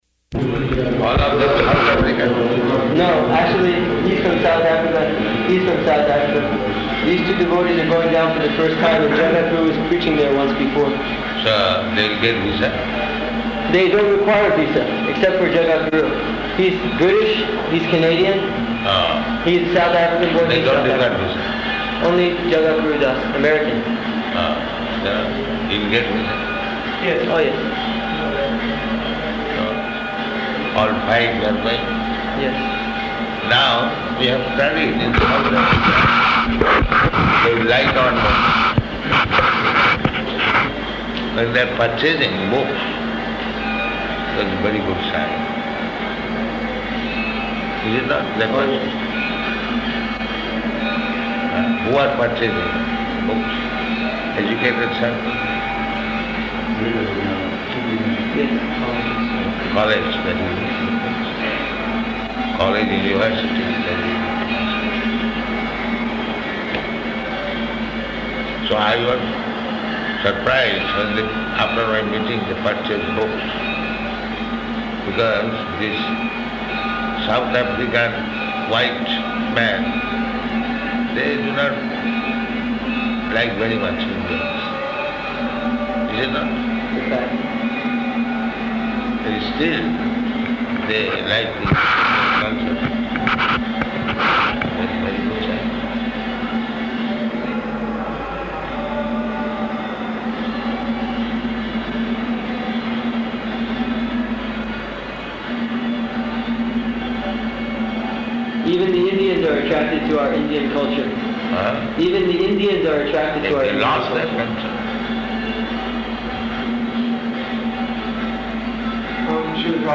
Room Conversation
Room Conversation --:-- --:-- Type: Conversation Dated: April 4th 1976 Location: Vṛndāvana Audio file: 760404R1.VRN.mp3 Prabhupāda: All of them are South African?